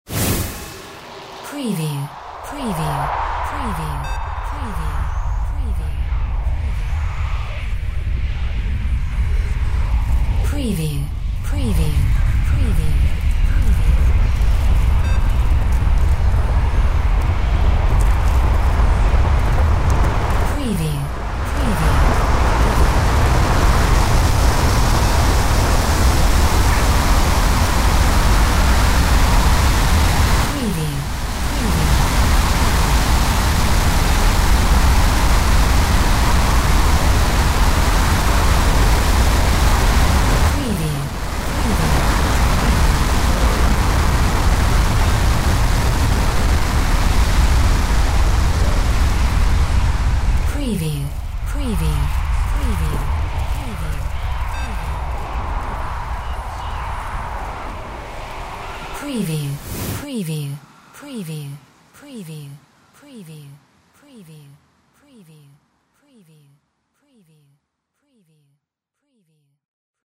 Wind Magic Full 01
Stereo sound effect - Wav.16 bit/44.1 KHz and Mp3 128 Kbps
previewSCIFI_MAGIC_WIND_FULL_WBSD01.mp3